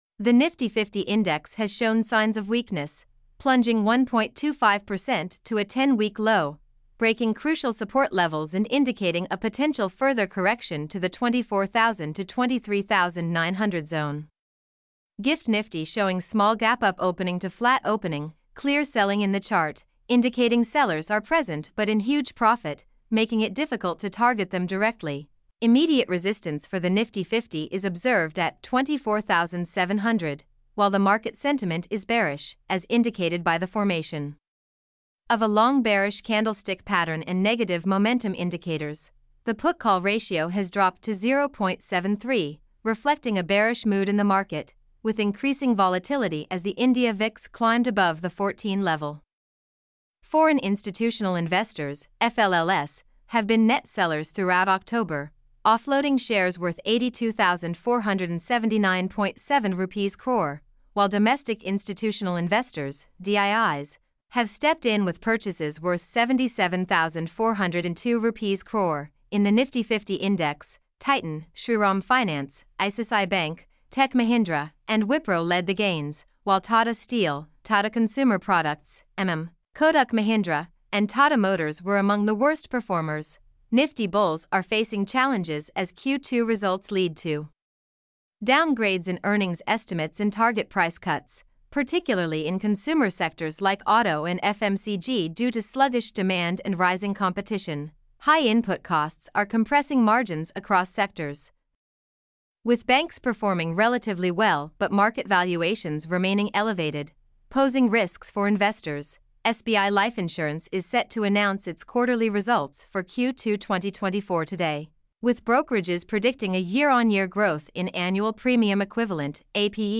mp3-output-ttsfreedotcom-14.mp3